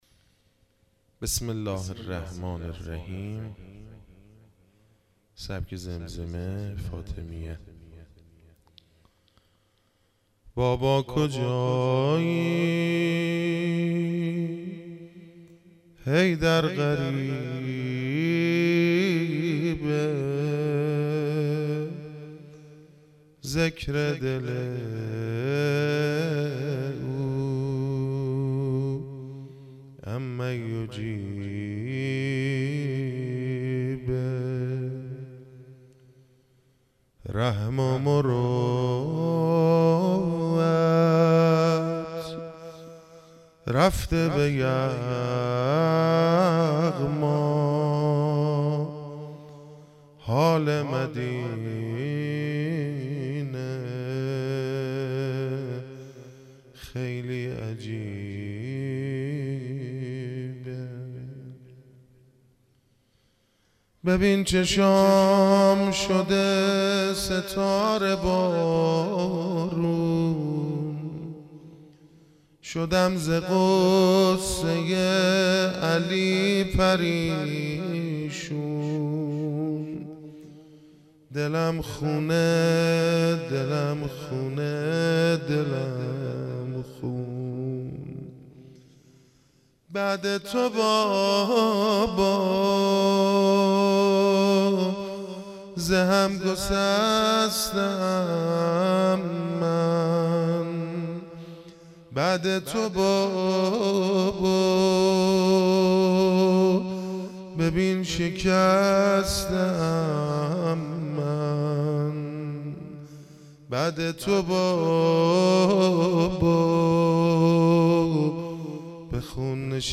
زمزمه - زمینه - شور
سبک محرم